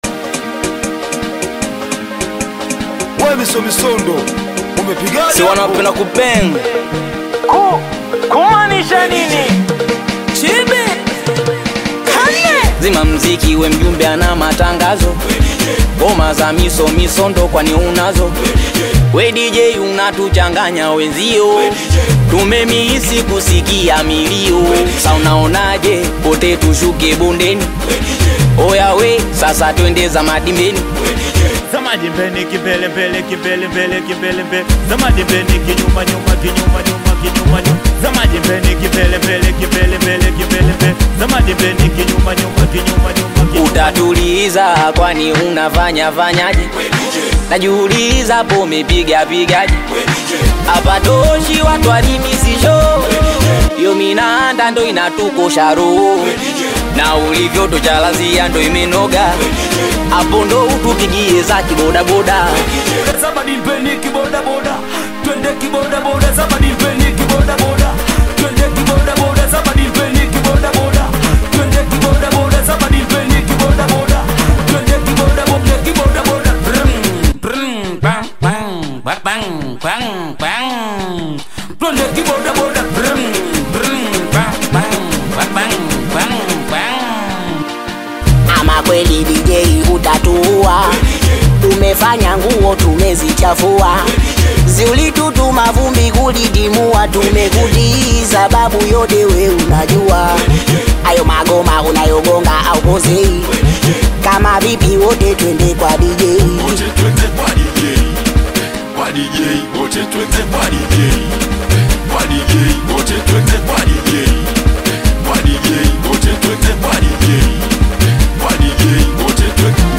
Singeli music track
This catchy new song